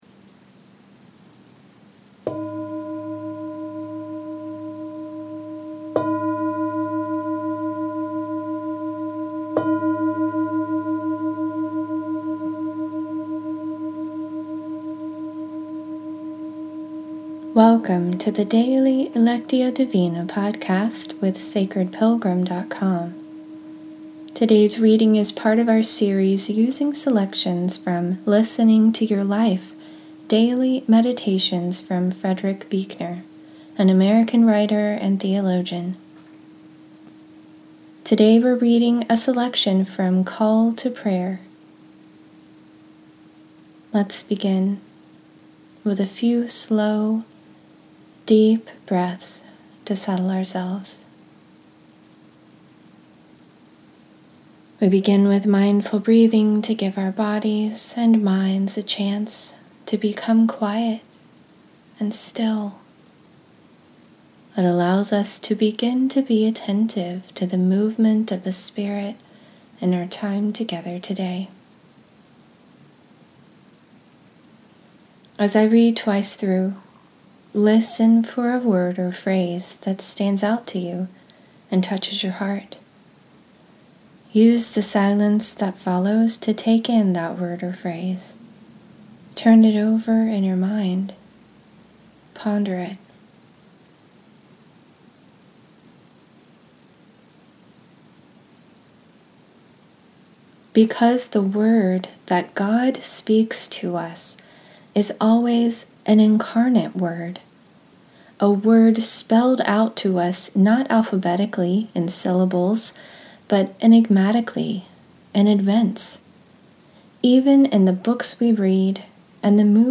In this episode, we’re continuing our series using selections from Listening to Your Life: Daily Meditations with Frederick Buechner, an American writer and theologian. Today we’re reading a selection from “Call to Prayer.”